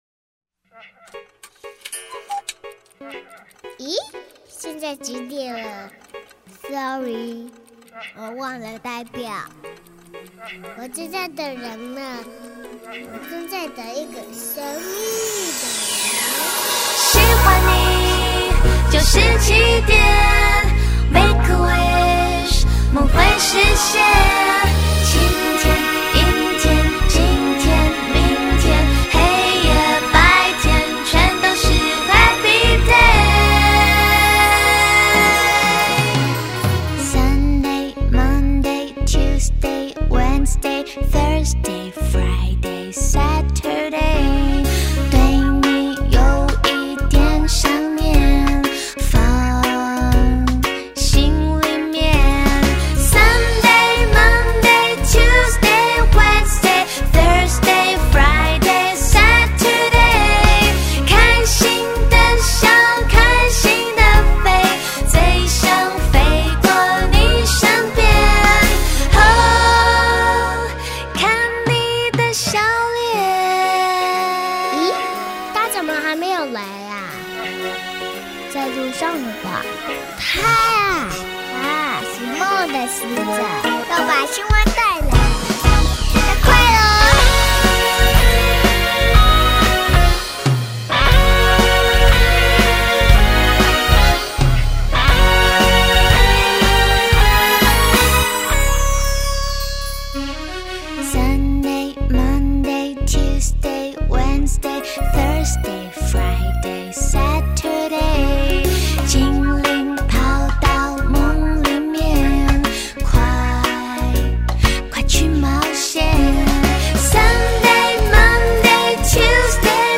香甜美音如茄红素